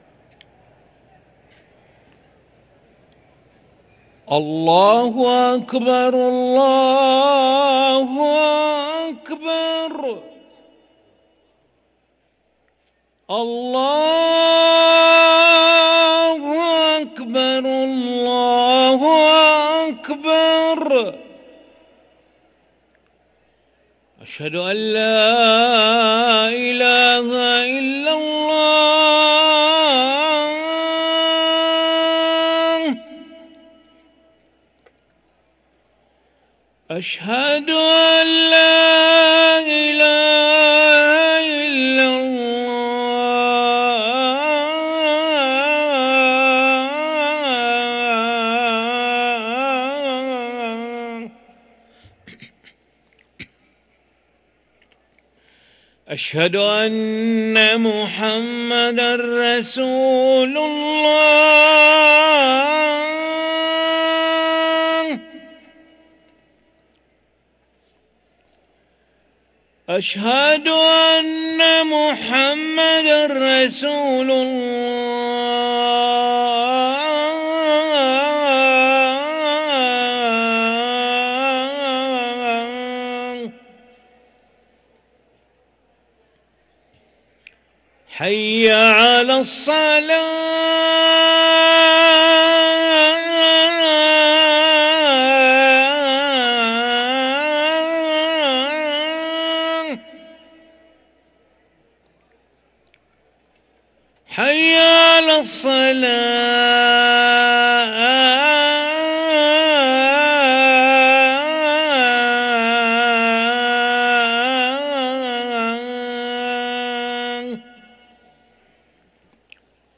أذان العشاء للمؤذن علي ملا الأحد 4 رمضان 1444هـ > ١٤٤٤ 🕋 > ركن الأذان 🕋 > المزيد - تلاوات الحرمين